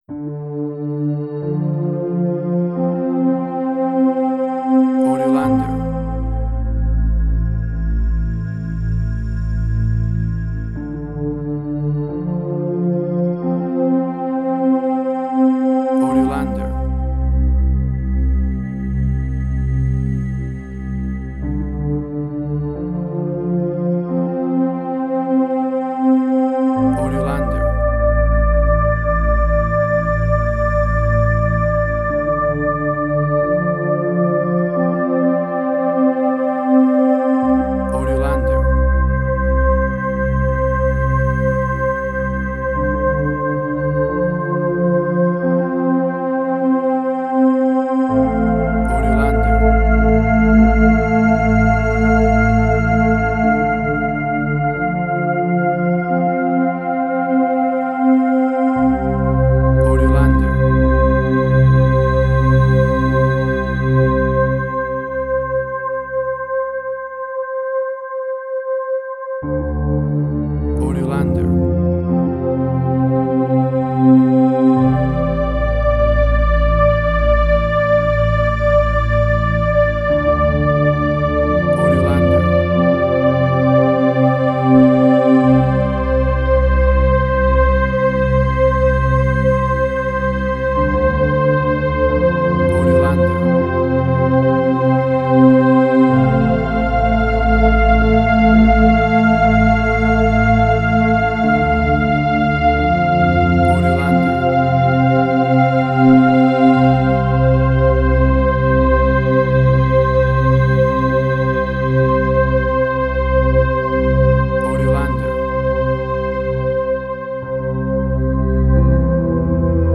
New Age.
emotional music